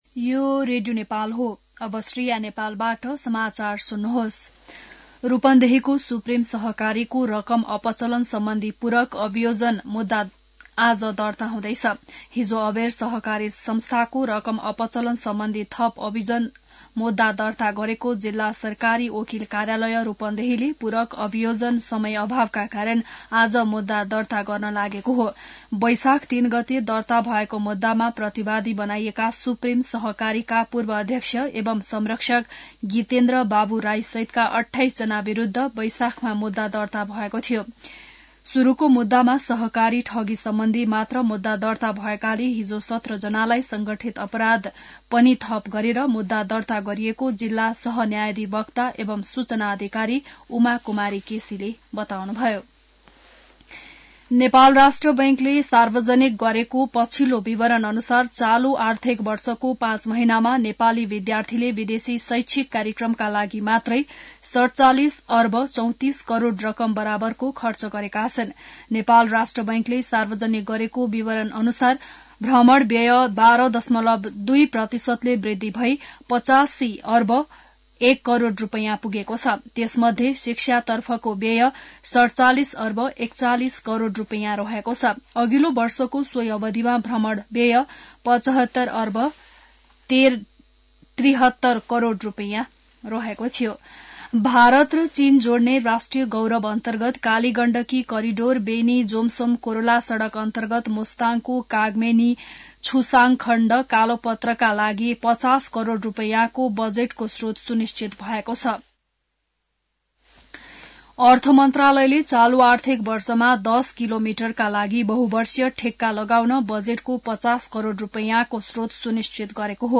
An online outlet of Nepal's national radio broadcaster
बिहान १० बजेको नेपाली समाचार : ५ माघ , २०८१